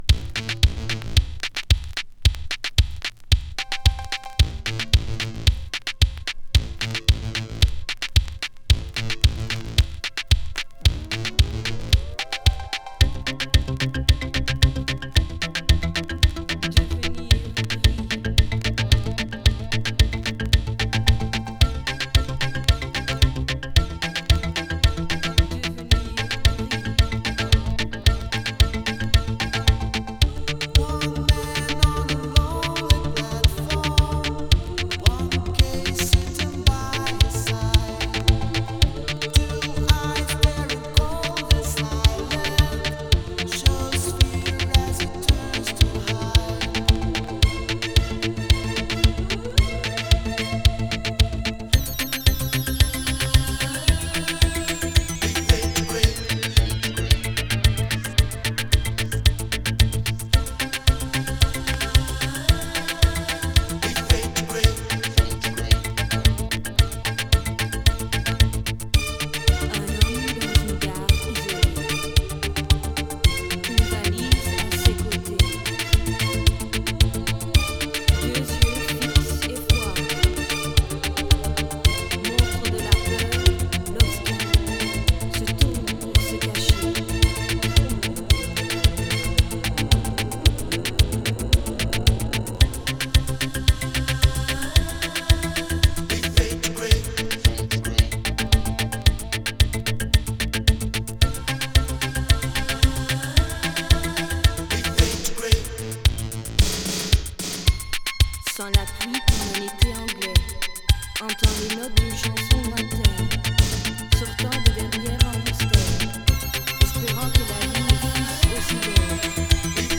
ベルギー産ニュービート・グループ。
【COSMIC】【NEW BEAT】